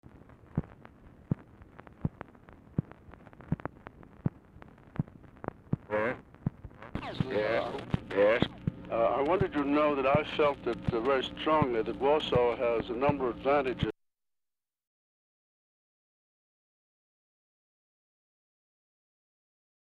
Telephone conversation # 12917, sound recording, LBJ and AVERELL HARRIMAN, 4/11/1968, 11:46AM | Discover LBJ
RECORDING ENDS BEFORE CONVERSATION IS OVER
Format Dictation belt
Location Of Speaker 1 Mansion, White House, Washington, DC